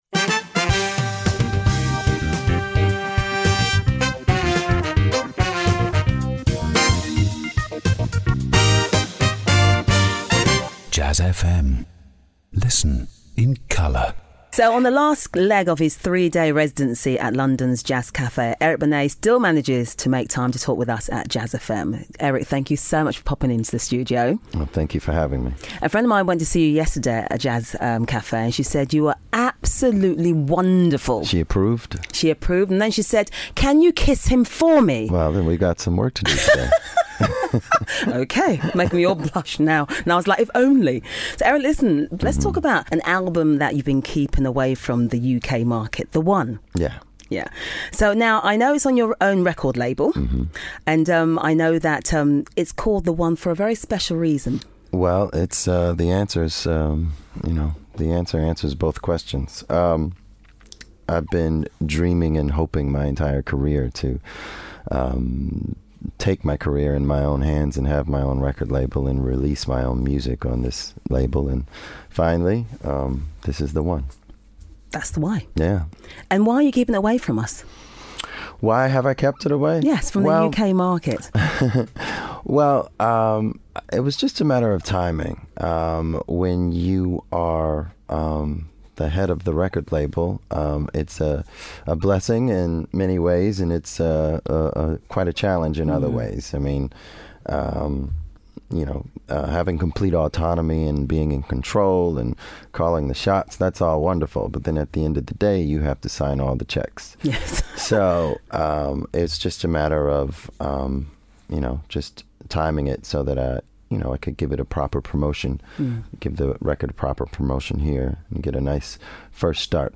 Interviews and Live Sessions / Eric Benet in Conversation